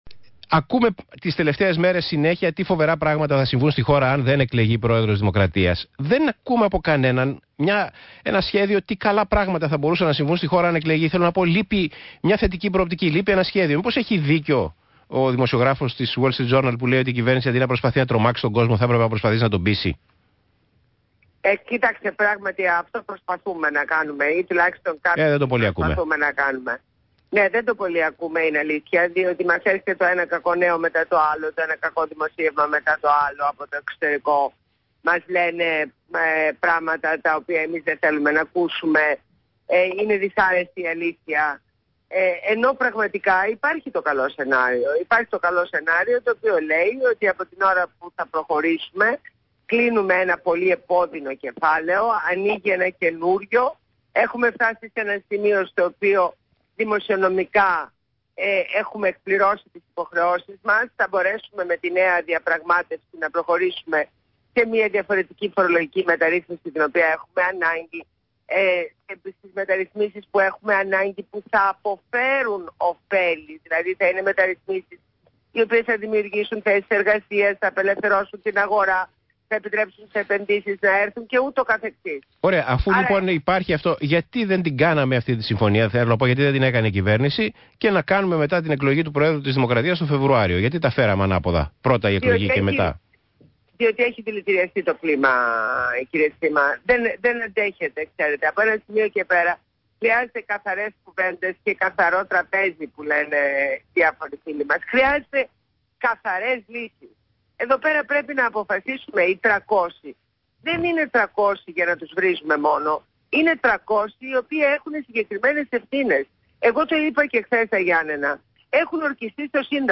Συνέντευξη στο ραδιόφωνο του ΣΚΑΙ στο δημοσιογράφο Π. Τσίμα.